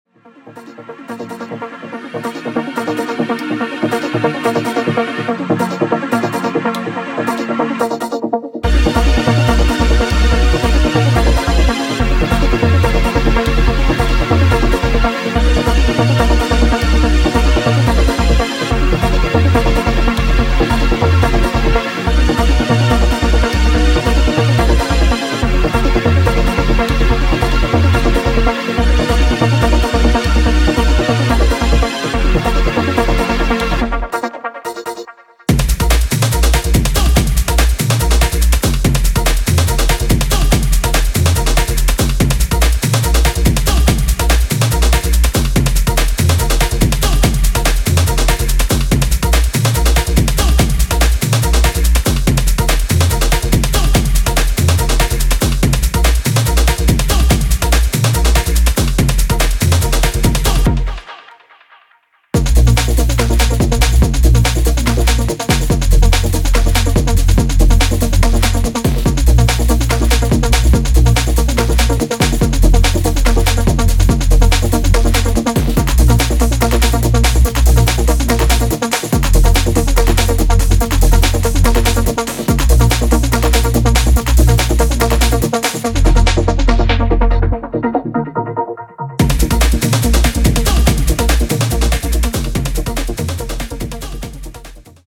deep breakbeat / Detroit version